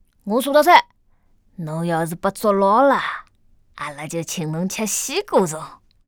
序章与第一章配音资产
c01_5残疾小孩_3.wav